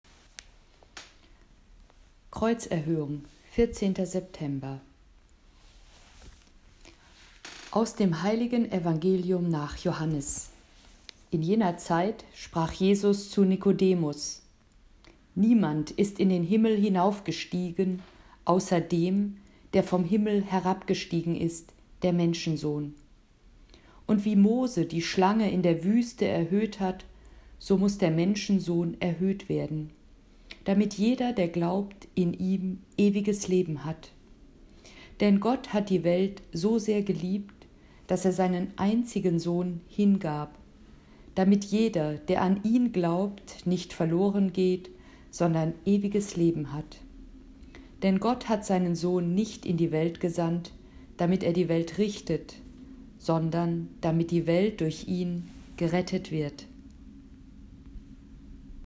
Sprechtraining
Audio-Datei zum Üben der Aussprache von liturgischen Texten